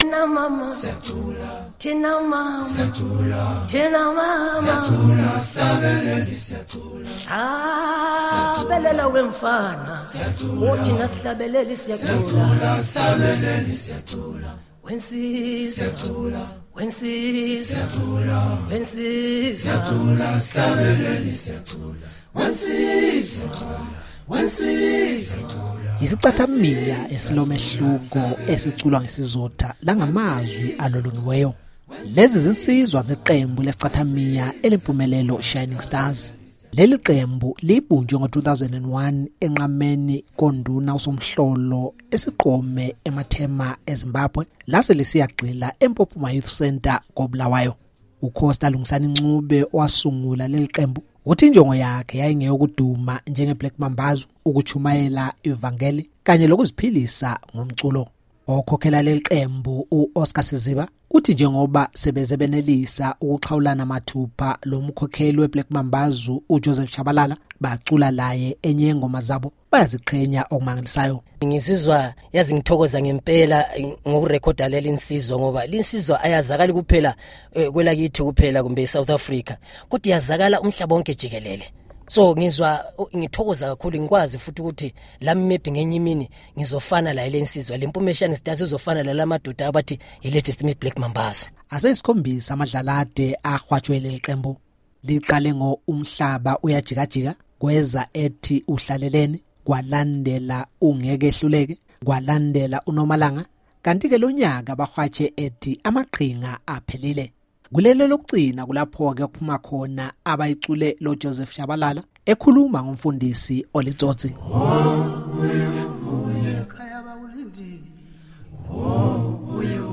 Yisichathamiya esilomehluko, esiculwa ngesizotha langamazwi alolongiweyo.